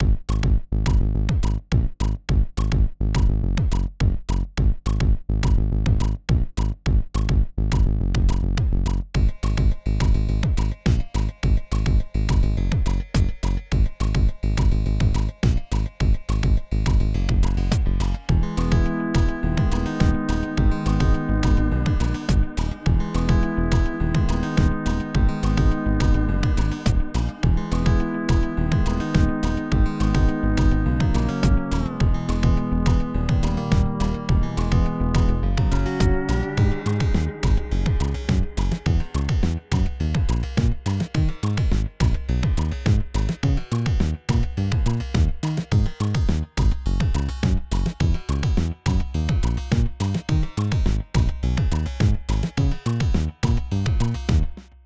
Finally some proper usage from the Hawaiian Guitar.101 Synth Bass is as versatile as ever.